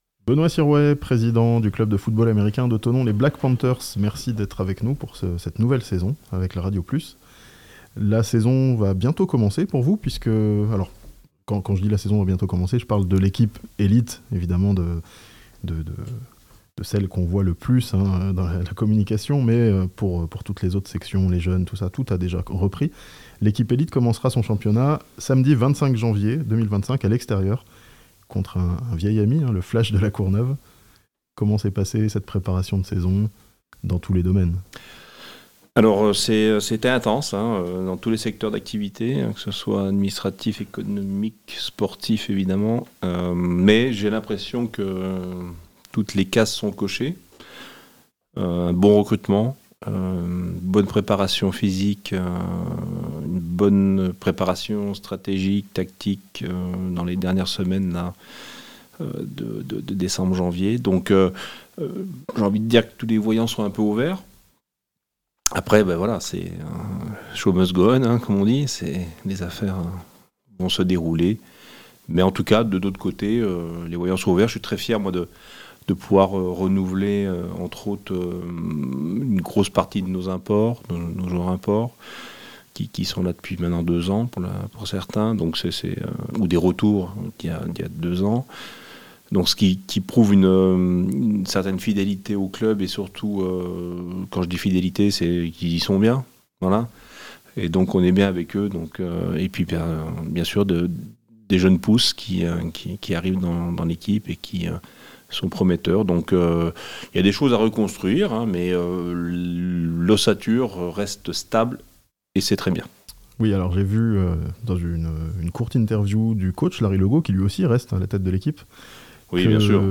Les Black Panthers de Thonon retrouvent les terrains samedi 25 janvier (interview)